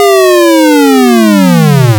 fall.ogg